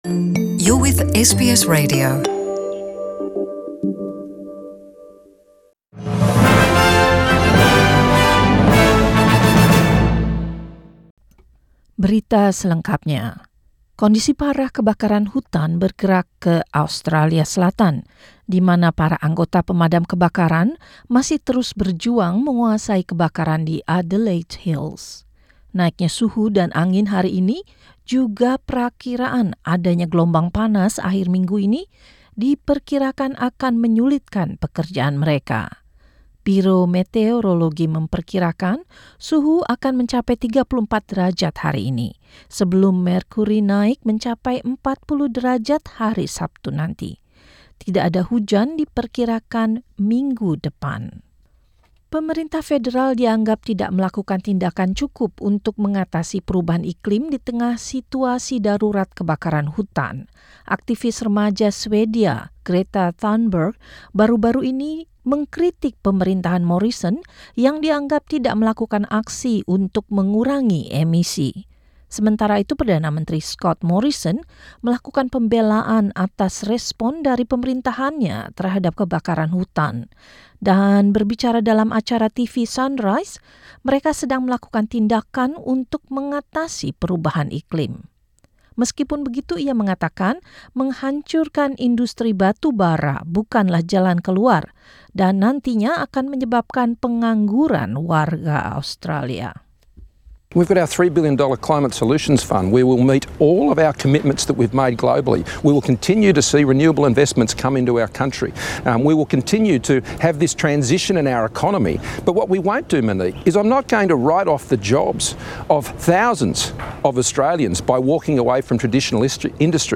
News in Indonesian 23 December 2019